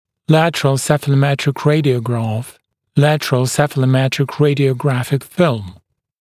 [‘lætərəl ˌsefələˈmetrɪk ‘reɪdɪəugrɑːf] [-græf] [‘lætərəl ˌsefələˈmetrɪk ˌreɪdɪəu’græfɪk fɪlm][‘лэтэрэл ˌсэфэлэˈмэтрик ‘рэйдиоугра:ф] [-грэф] [‘лэтэрэл ˌсэфэлэˈмэтрик рэйдиоу’грэфик филм]боковая цефалограмма, боковой цефалографический снимок, телерентгенограмма головы в боковой проекции